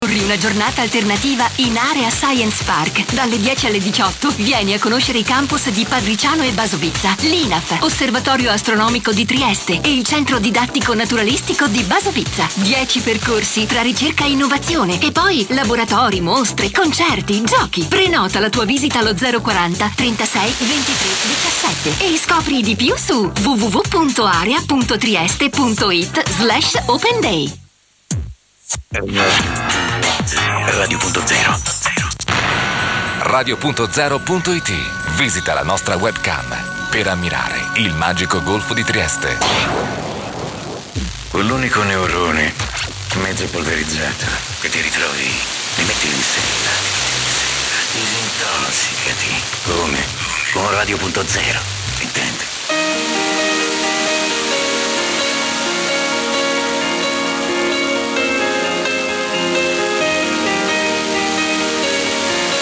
I have never really properly identified any Italian studio links before, but today two were strong.